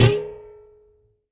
stringSnap.ogg